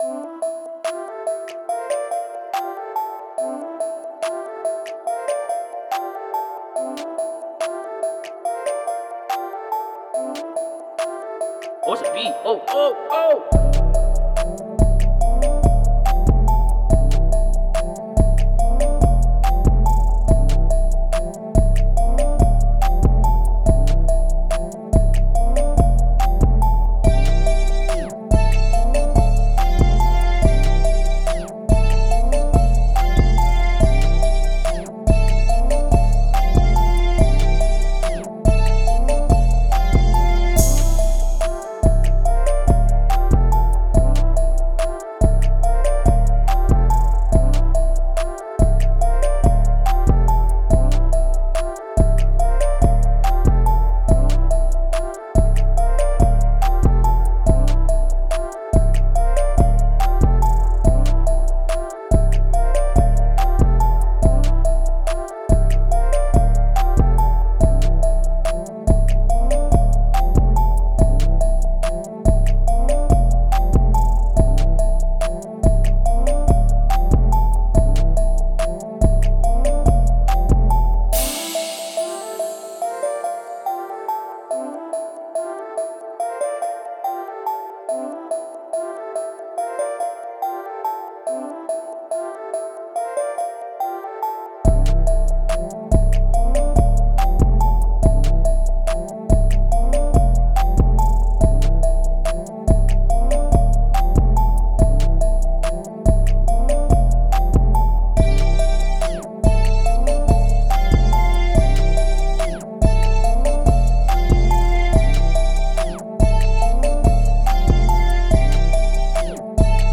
Key:Dm